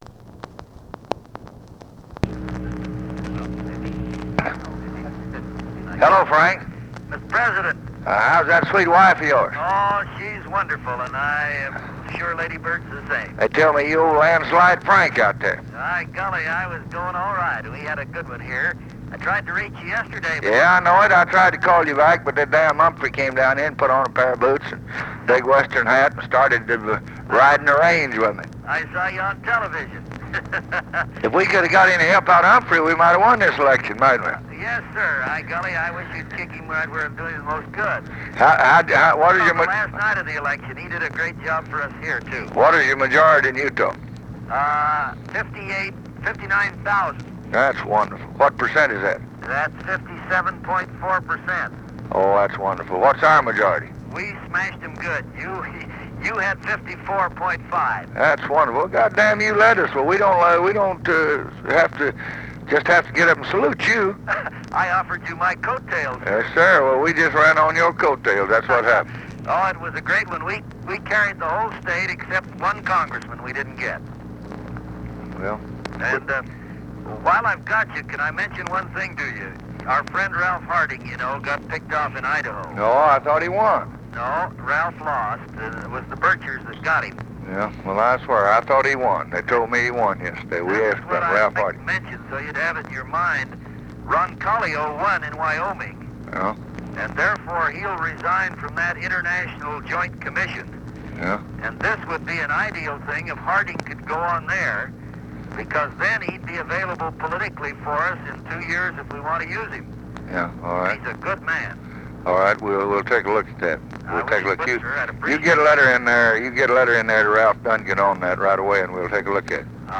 Conversation with FRANK MOSS and HUBERT HUMPHREY, November 5, 1964
Secret White House Tapes